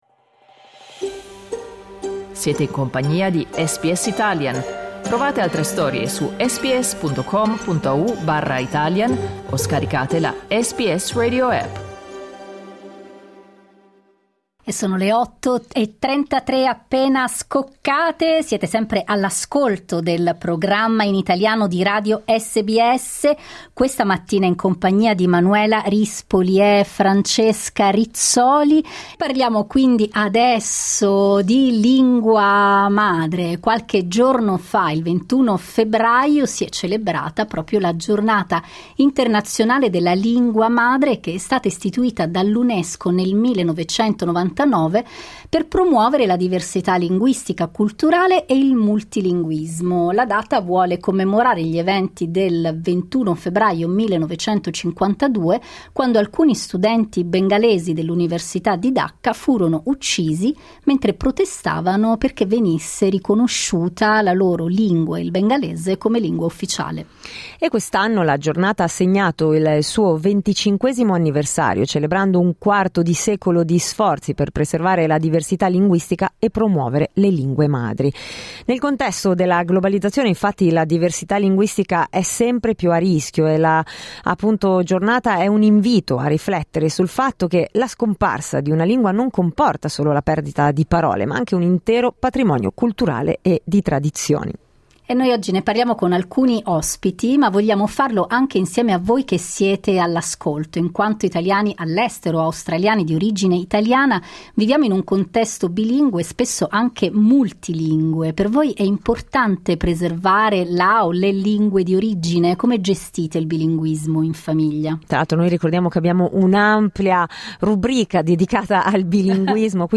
In diretta abbiamo chiesto quanto sia importante per voi preservare la lingua d'origine e come gestite il bilinguismo o il plurilinguismo in famiglia.